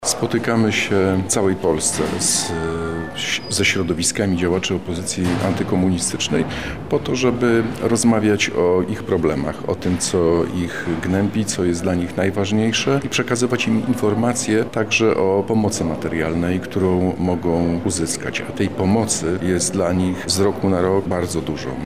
Większe wsparcie dla działaczy opozycji antykomunistycznej. Minister Parell spotkał się z kombatantami w Lublinie.
Lech Parell– podkreśla minister Lech Parell